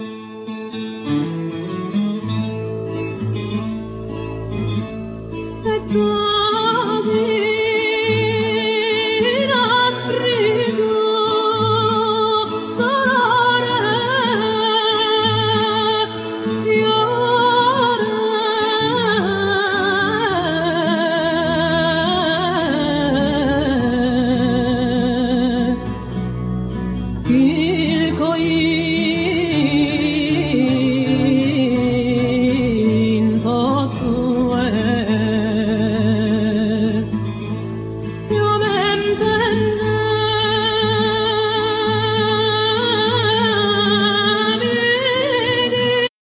Vocal
Solo guitar,Harp,Mandola
Acoustic guitar
Keyboards
Double bass
Flute,Bottles
Percussions
Alto & soprano saxes
Melodeon